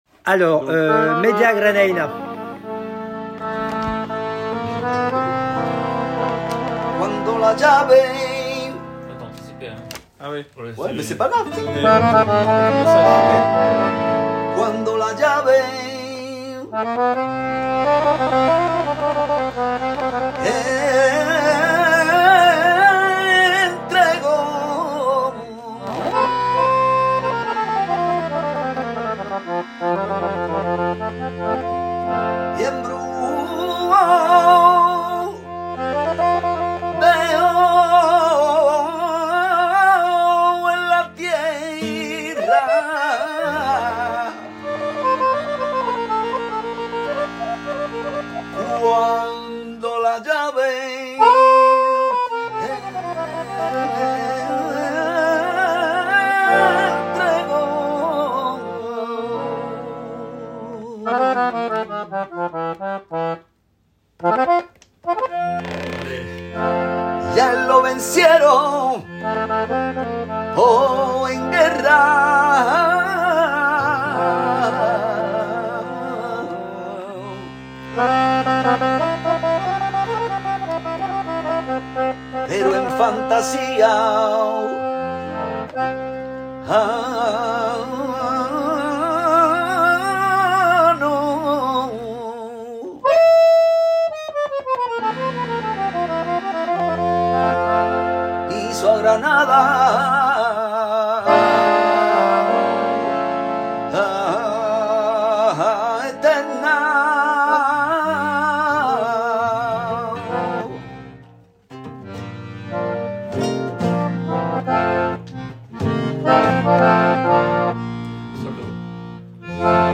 2_4 – chant media granaina (audio):
2_4 - media granaina.mp3